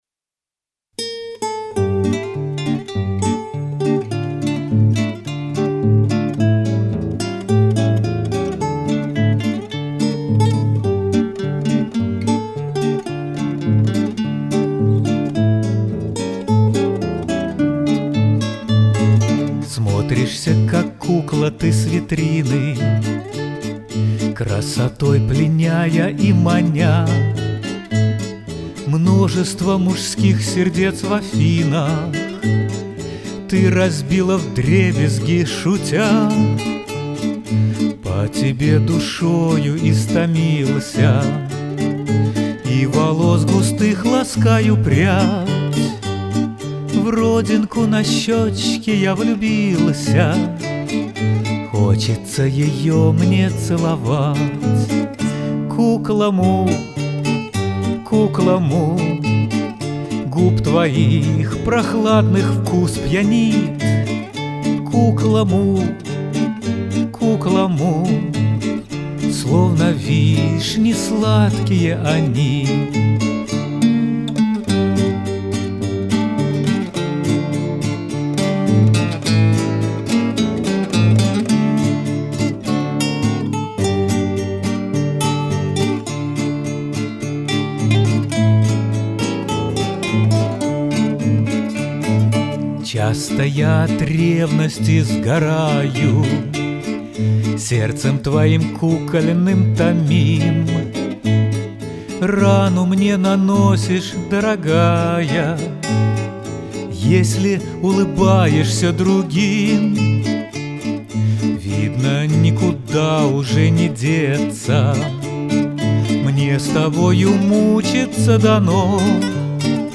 Вот еще старое танго